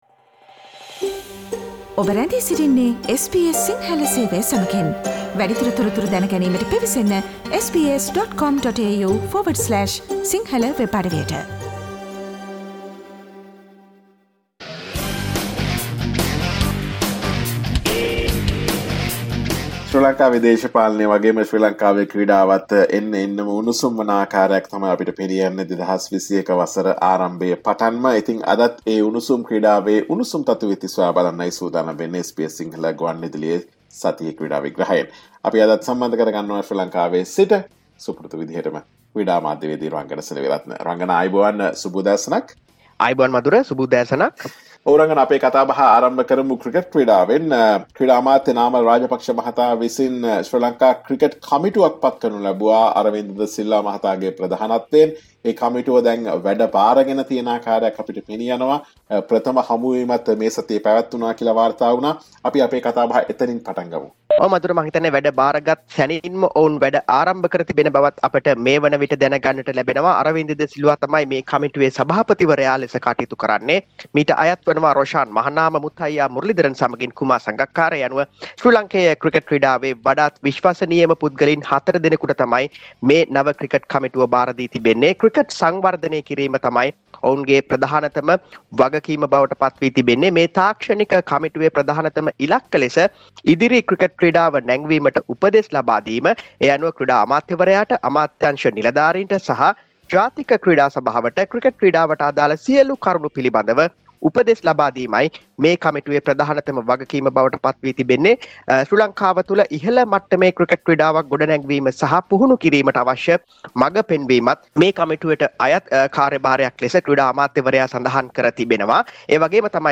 SBS Sinhala Sports Wrap with Sports Journalist